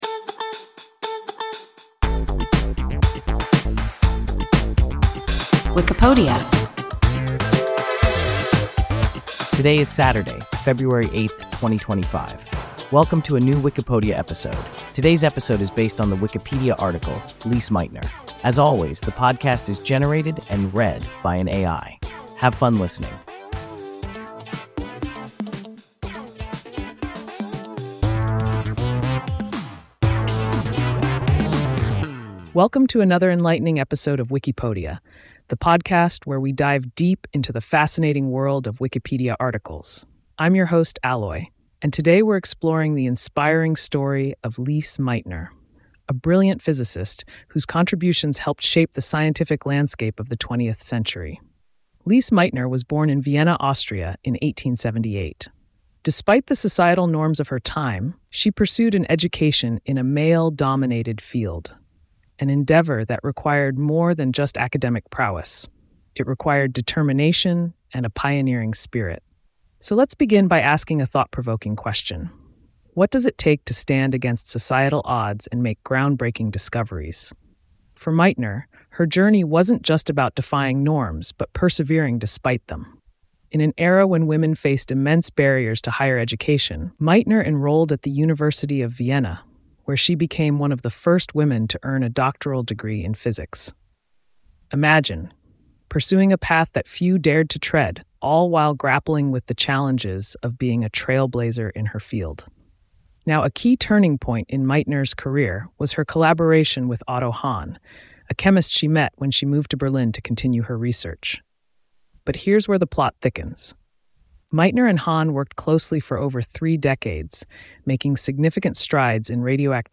Lise Meitner – WIKIPODIA – ein KI Podcast